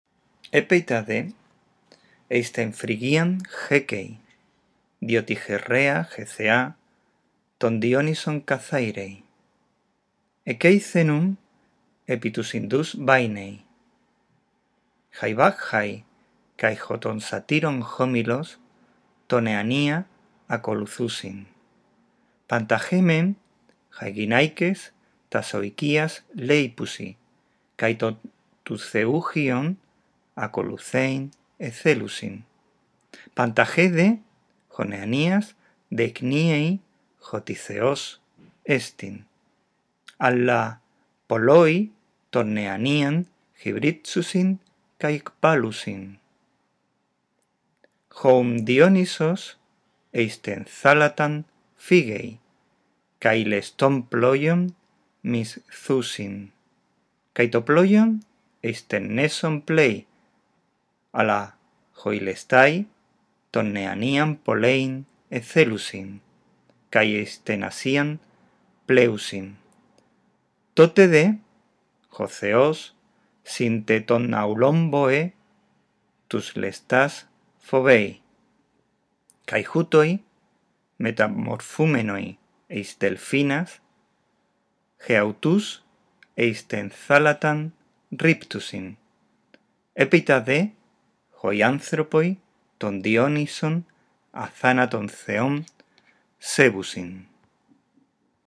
Escucha a continuación el archivo de audio y atiende a la pronunciación.